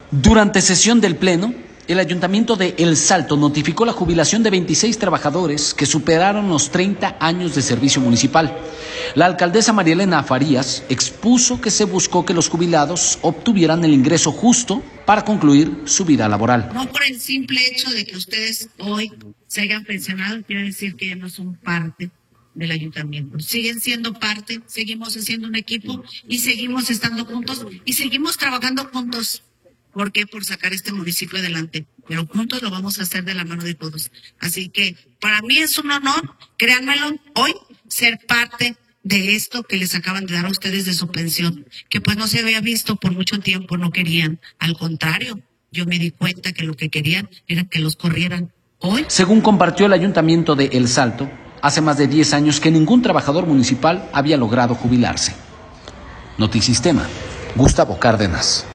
Durante sesión del pleno, el Ayuntamiento de El Salto notificó la jubilación de 26 trabajadores que superaron los 30 años de servicio municipal. La alcaldesa, María Elena Farías, expuso que se buscó que los jubilados obtuvieran un ingreso justo y digno para concluir su vida laboral.